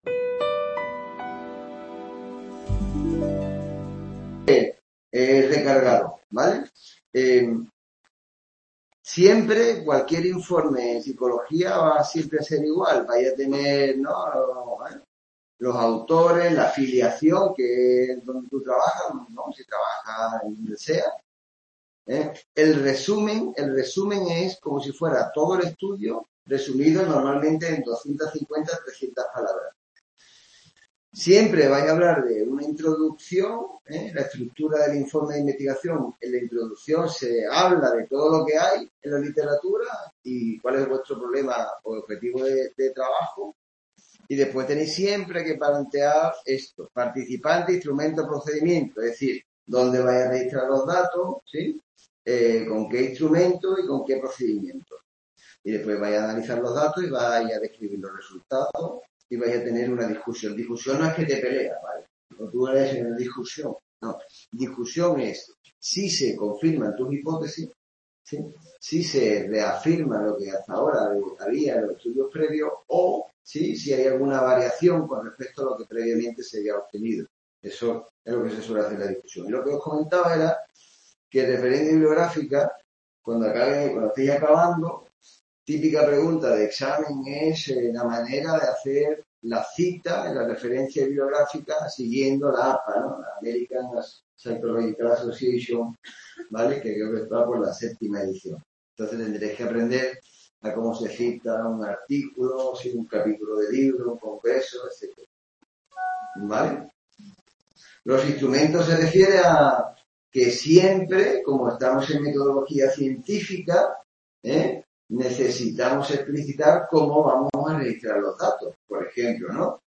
Hubo interrupción en la grabación de este día.
Video Clase